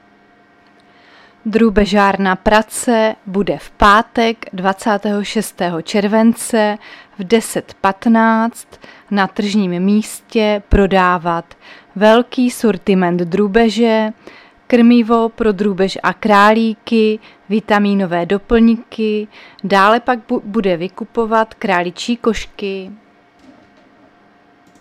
Záznam hlášení místního rozhlasu 23.7.2024